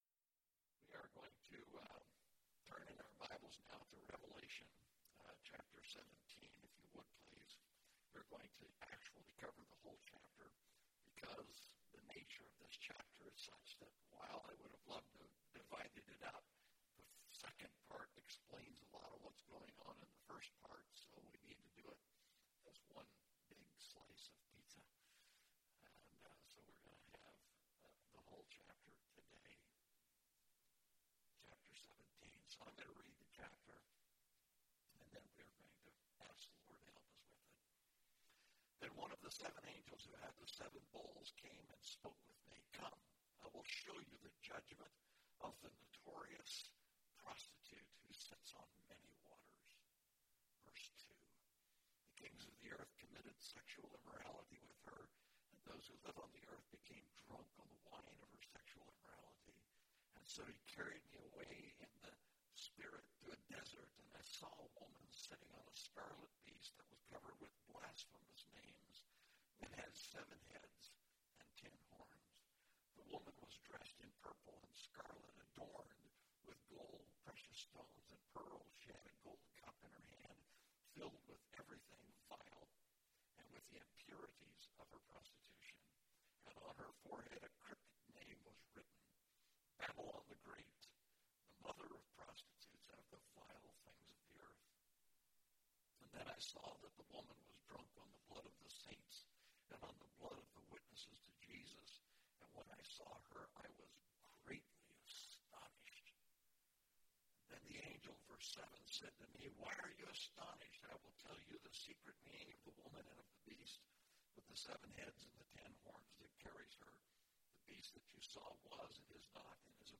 The Lamb Wins (Revelation 17:1-18) – Mountain View Baptist Church